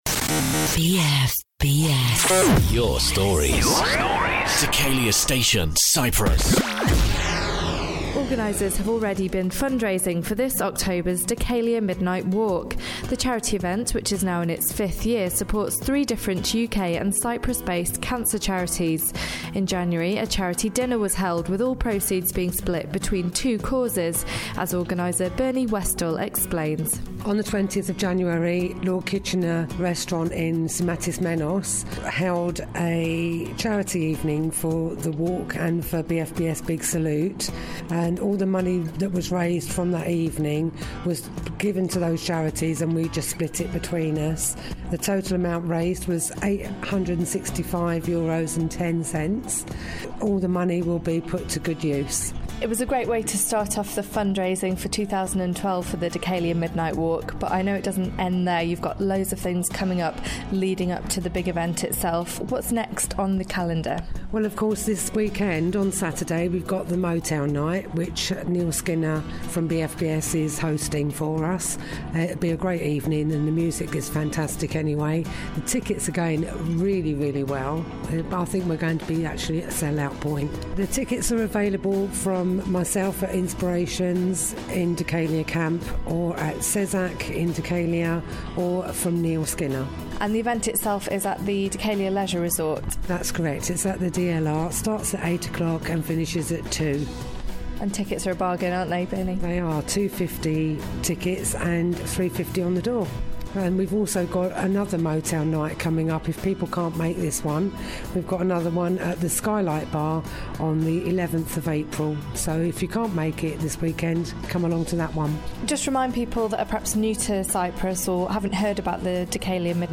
BFBS report on this year's fundraising for the Dhekelia Midnight Walk, supporting three cancer charities in Cyprus and the UK...